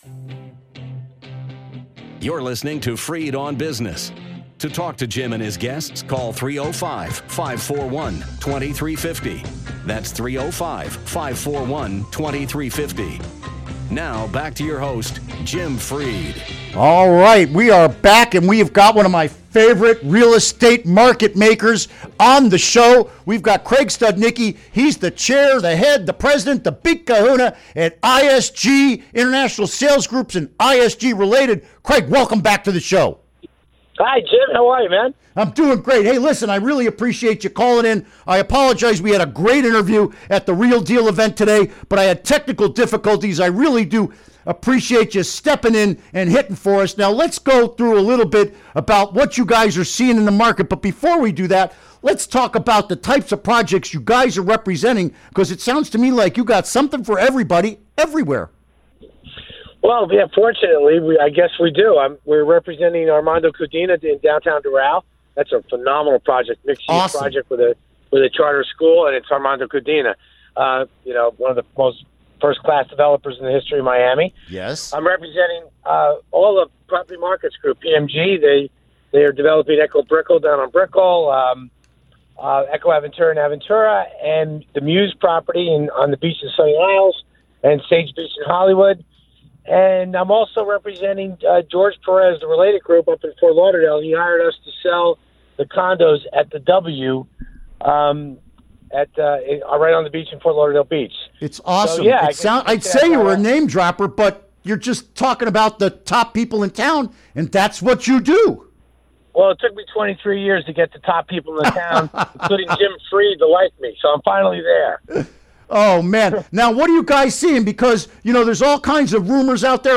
Interview Segment Episode 390: 10-20-16 Download Now!